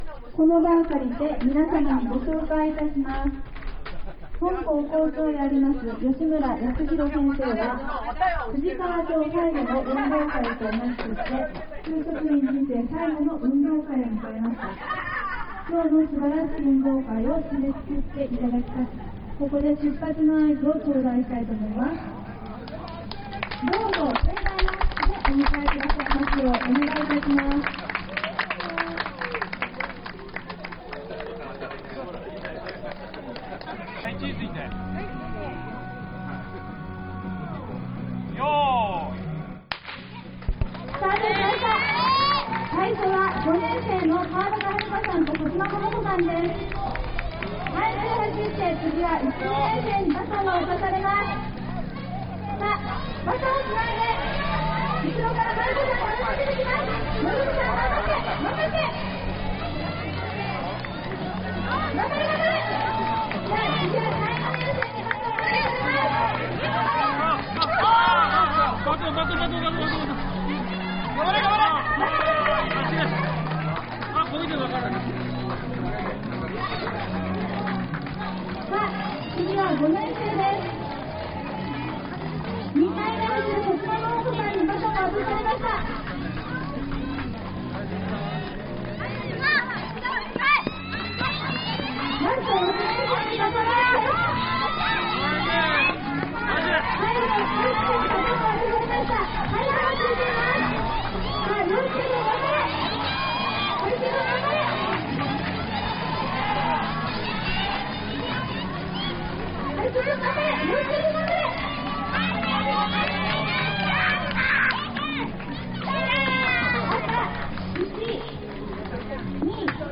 第70回 薩摩川内市立藤川小学校秋季大運動会 が行われました。